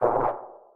File:Sfx creature penguin hop voice 02.ogg - Subnautica Wiki
Sfx_creature_penguin_hop_voice_02.ogg